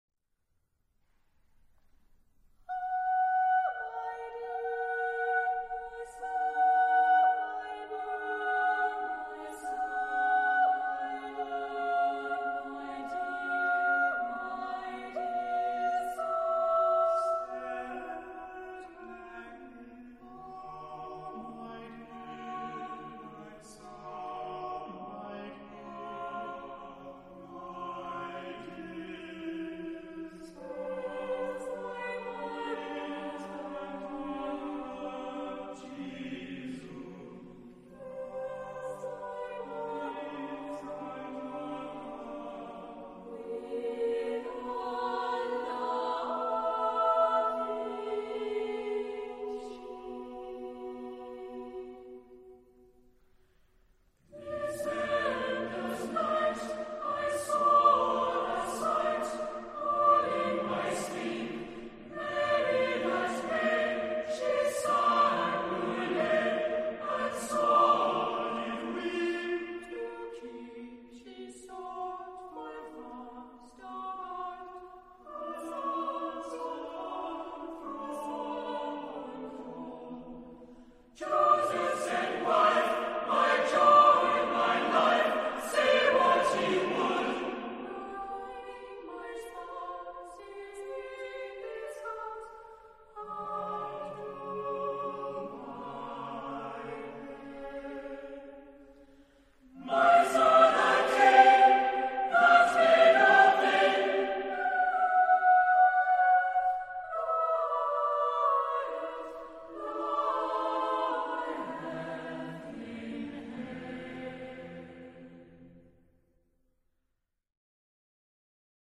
Epoque: 20th century
Genre-Style-Form: Sacred ; Christmas song ; Carol
Type of Choir: SATB (div.)  (4 mixed voices )
Tonality: atonal